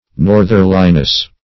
Search Result for " northerliness" : The Collaborative International Dictionary of English v.0.48: Northerliness \North"er*li*ness\, n. The quality or state of being northerly; direction toward the north.
northerliness.mp3